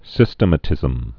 (sĭstə-mə-tĭzəm, sĭ-stĕmə-)